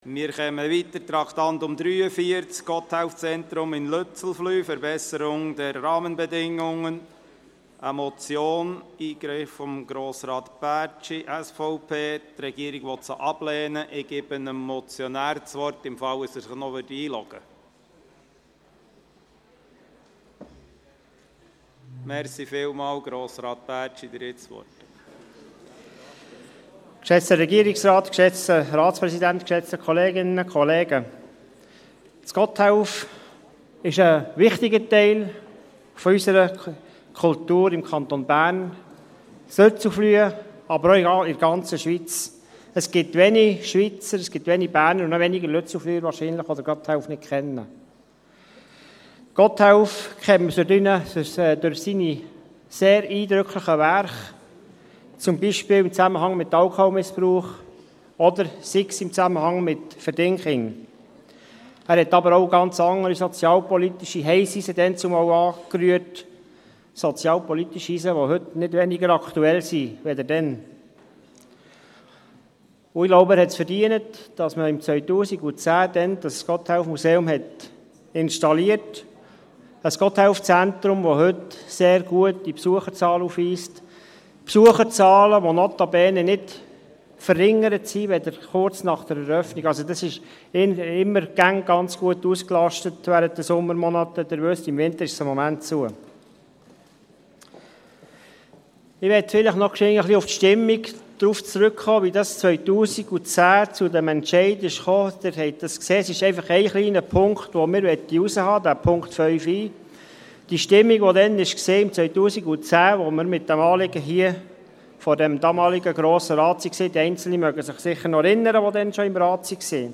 Hier können Sie die Verhandlungen des Grossen Rates als Podcast nachverfolgen.
verhandlung_grossrat_motion_baertschi.mp3